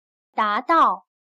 达到/dádào/Lograr